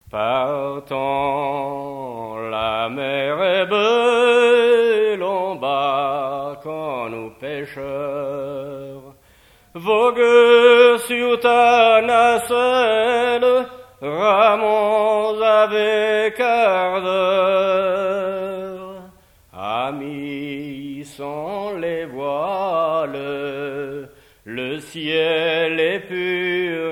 Île-d'Yeu (L')
Genre strophique
chansons tradtionnelles
Pièce musicale inédite